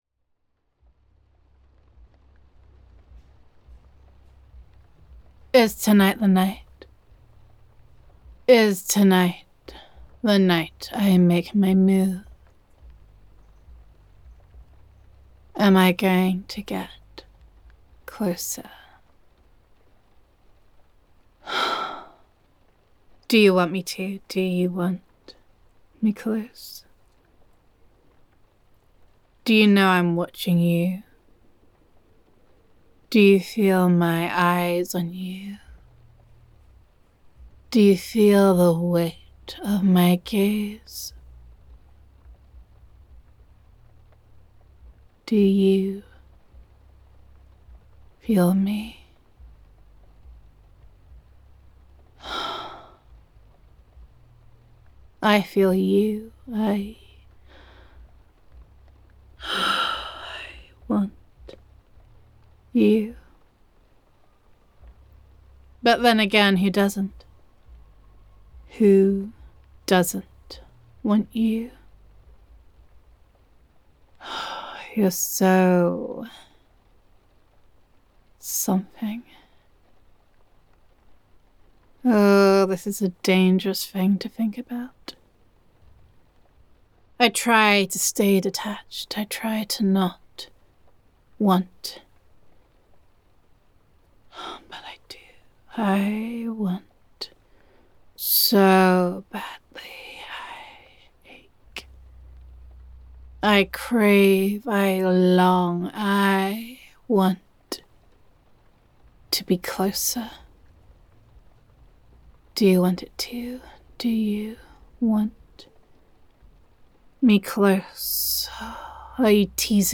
[F4A] Creeping Up on You [Yandere Admirer Roleplay][Stalking][Obsession][Infatuation][Dark][Jealousy][You Have Always Been Mine][Longing][Gender Neutral][You’ve Felt Her Eyes on You, and You Know She’s Getting Closer]